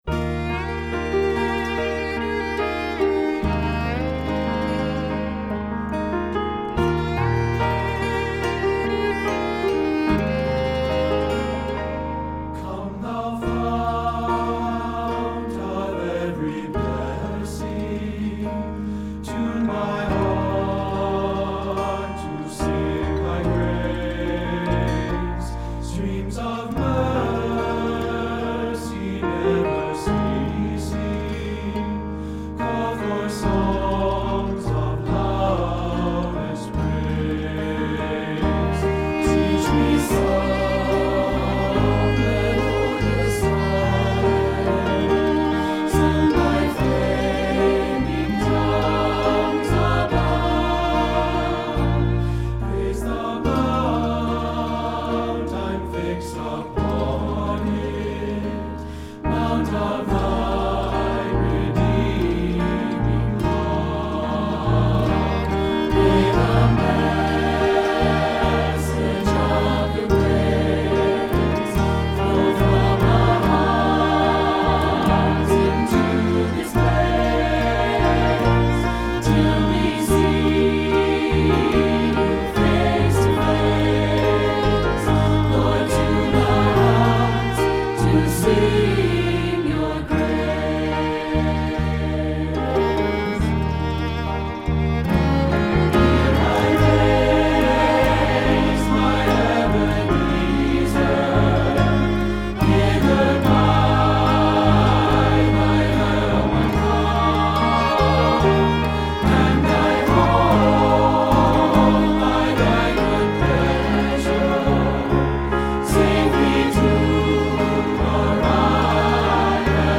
ballad has a gentle Appalachian feel
Voicing SATB